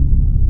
GREYPAD.wav